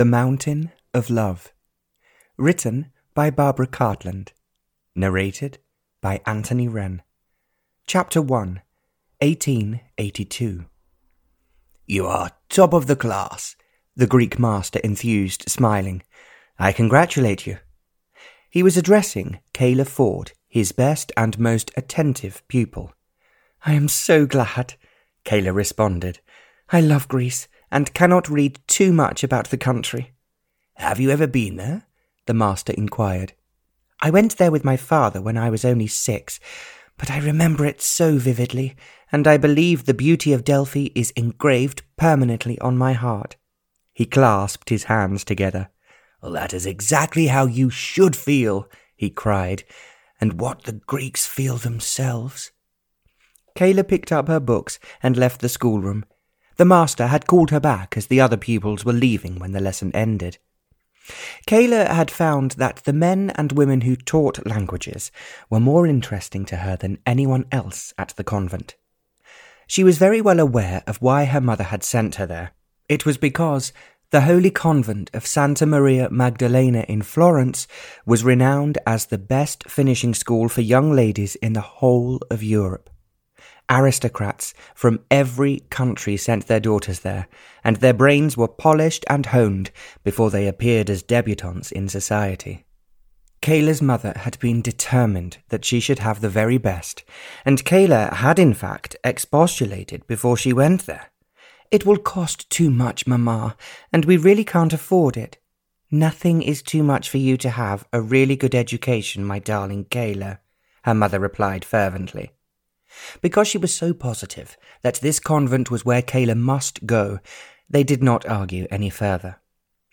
The Mountain of Love (EN) audiokniha
Ukázka z knihy